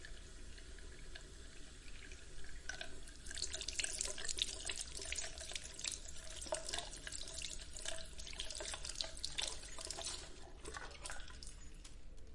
洗手池
描述：洗手在水槽里。记录在Zoom H6上。 Wav文件。记录在水槽附近
Tag: 水槽 洗净 洗涤 浴室